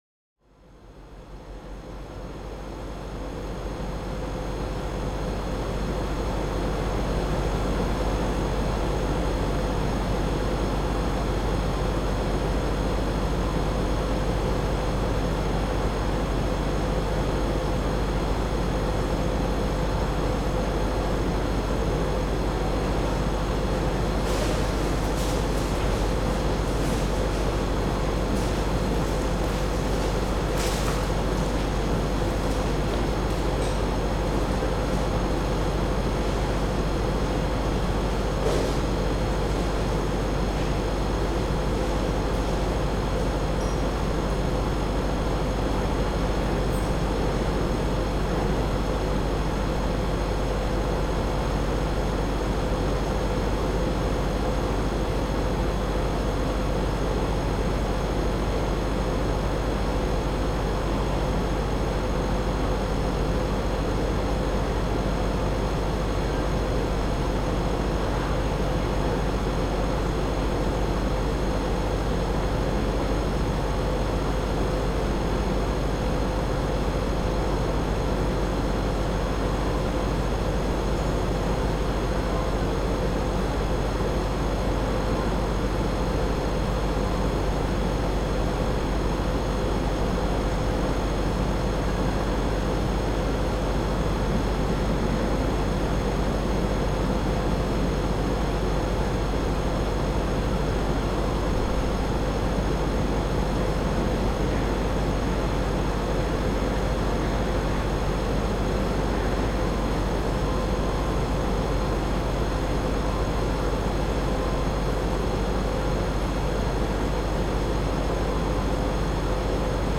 AMB_Scene01_Ambience_L.ogg